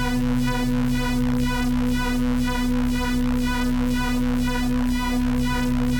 Index of /musicradar/dystopian-drone-samples/Tempo Loops/120bpm
DD_TempoDroneD_120-B.wav